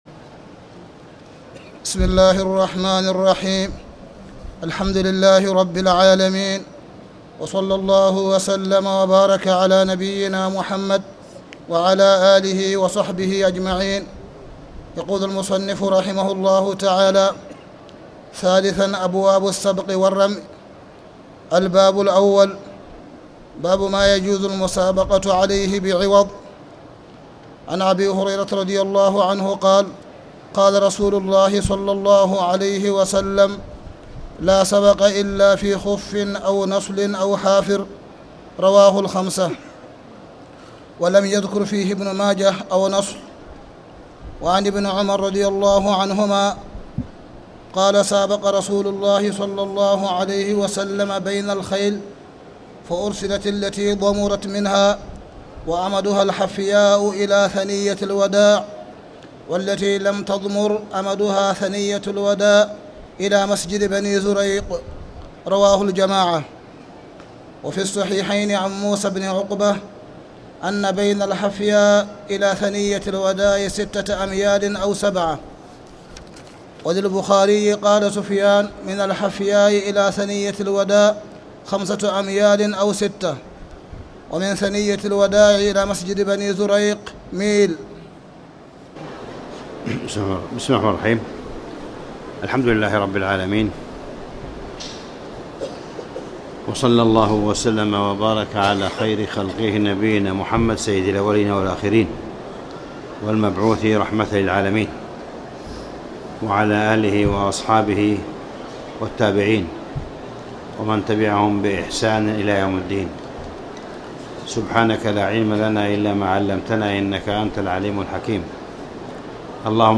تاريخ النشر ٥ رمضان ١٤٣٨ هـ المكان: المسجد الحرام الشيخ: معالي الشيخ أ.د. صالح بن عبدالله بن حميد معالي الشيخ أ.د. صالح بن عبدالله بن حميد باب ما يجوز المسابقة عليه بعوض